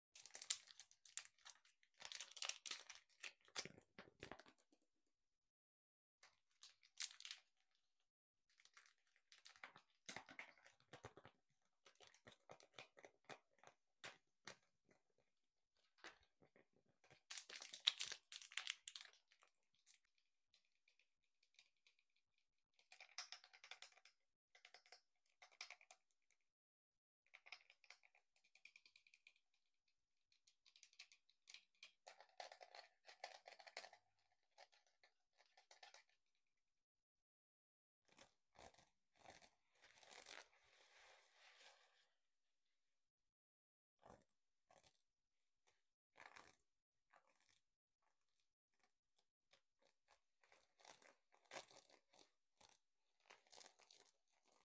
Asmr on random things.